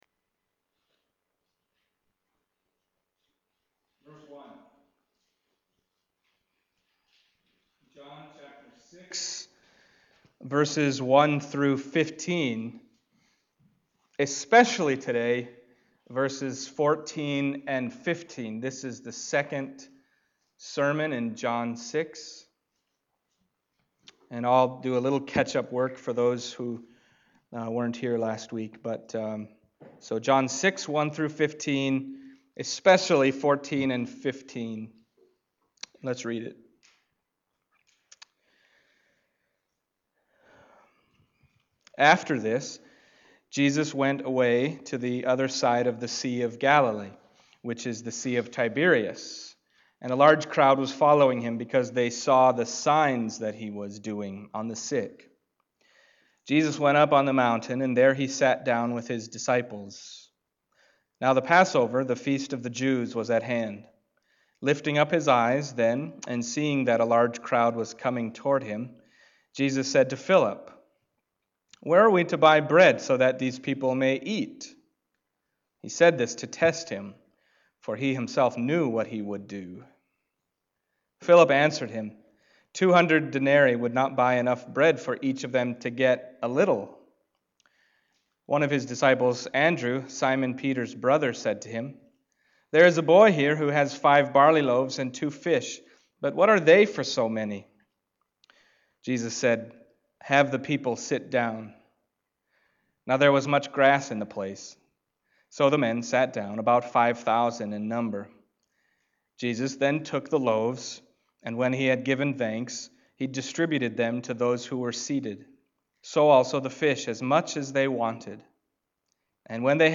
John 6:1-15 Service Type: Sunday Morning John 6:1-15 « Jesus